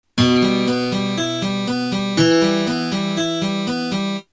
Способы аккомпанимента перебором
Em (4/4)